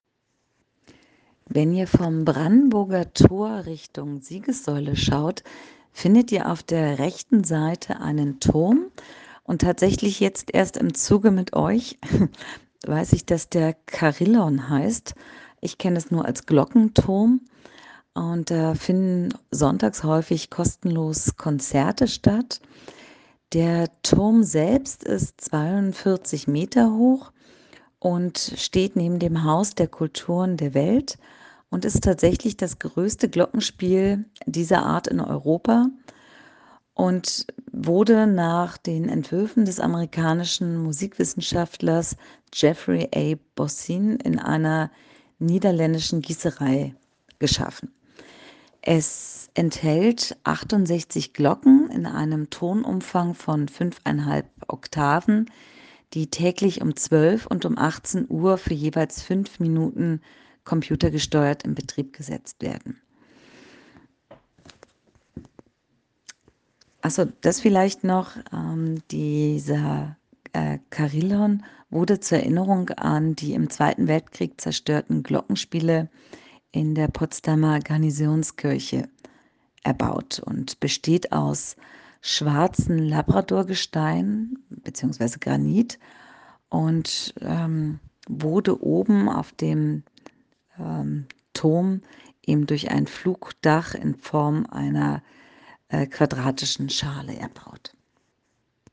Berlinfahrt – Carillon/Tiergarten
CarillonImTiergarten.ogg